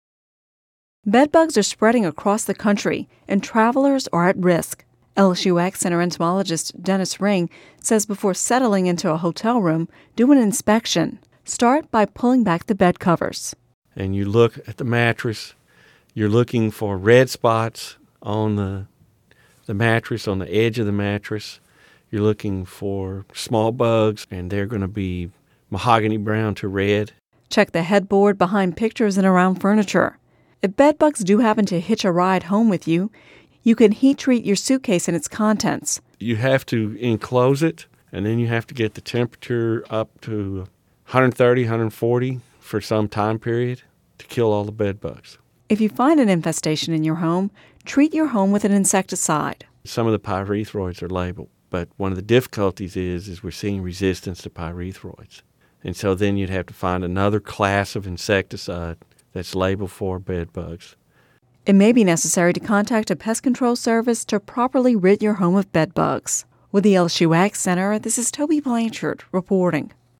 (Radio News 10/11/10) Bedbugs are spreading across the country, and travelers are at risk.